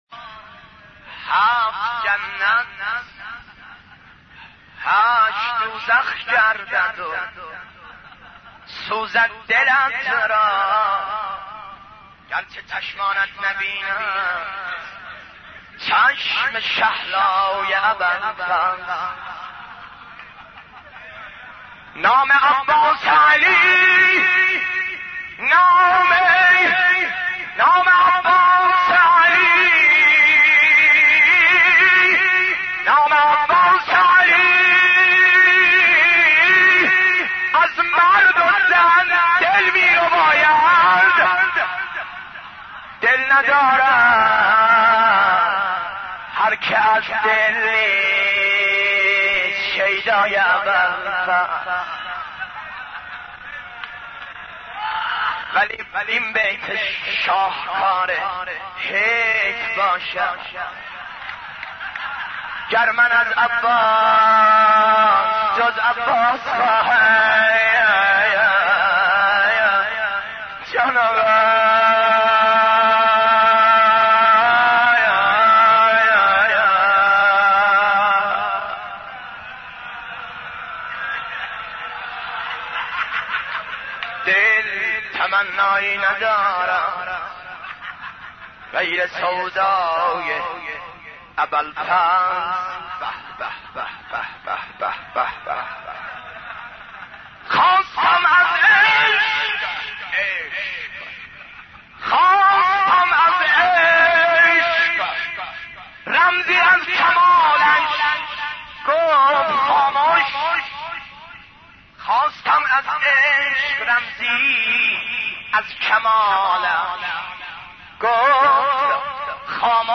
حضرت عباس ع ـ مداحی 2